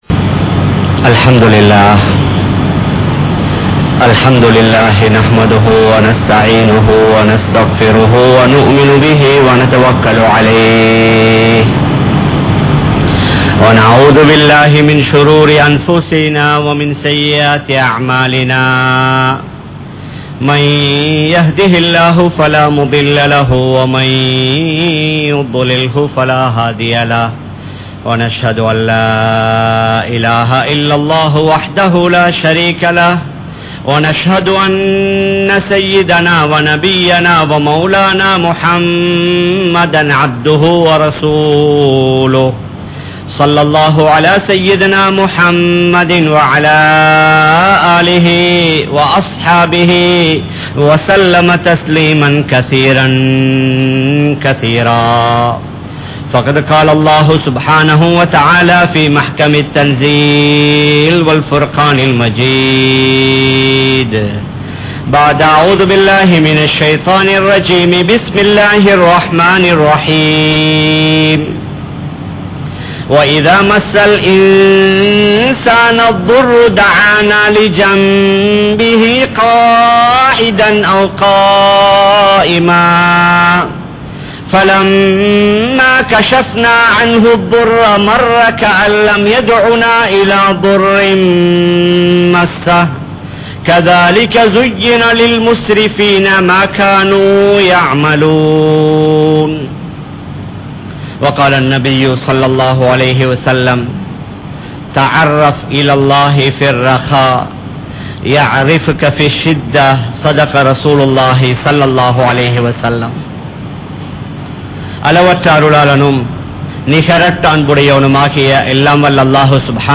Nilamaihalai Maattrufavan Allah (நிலமைகளை மாற்றுபவன் அல்லாஹ்) | Audio Bayans | All Ceylon Muslim Youth Community | Addalaichenai
Kollupitty Jumua Masjith